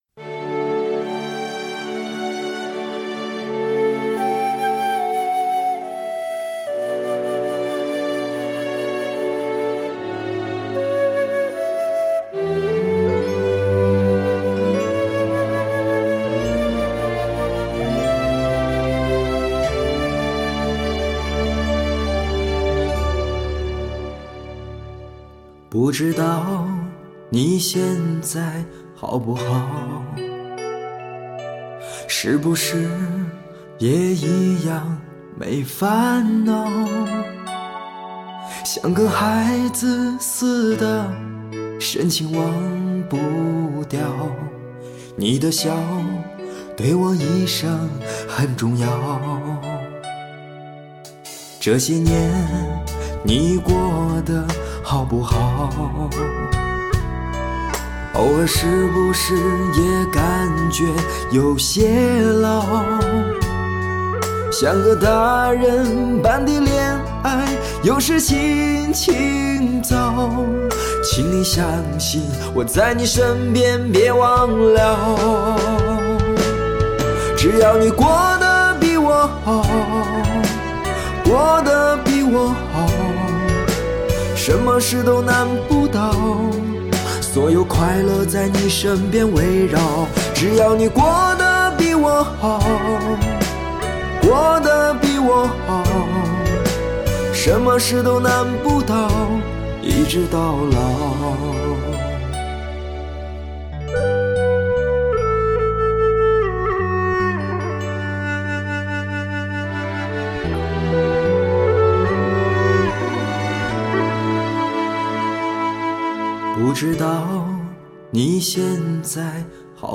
飘荡的音乐渐渐地开始弥散，凝视着画面里那张还年轻的脸，沉浸在这个温柔淡淡忧伤浅浅的歌声里，心情荡漾在那爱情依旧的昨日。